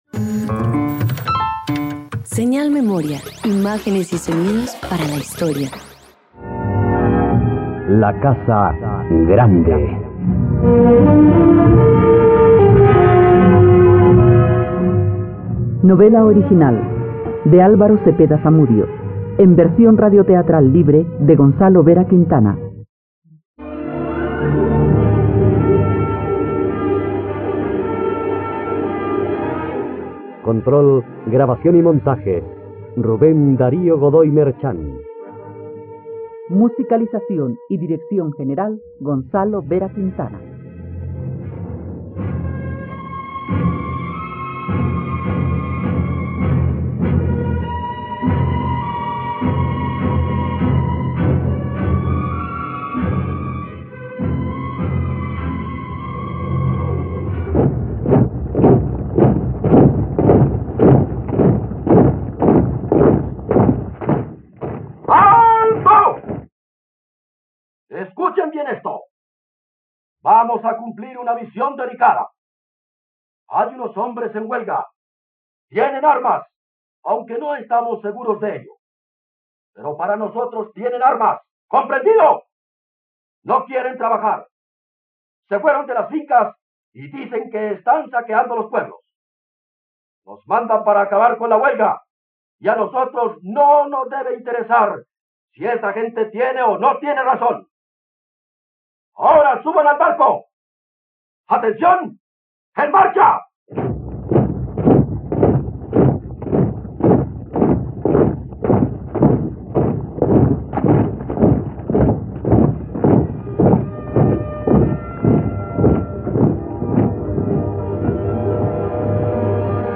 La casa grande - Radioteatro dominical | RTVCPlay